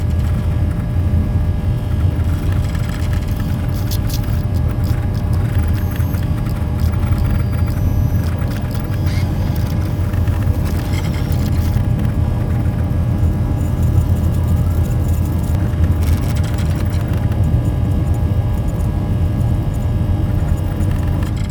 cockpit.ogg